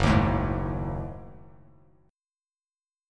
F#m6add9 chord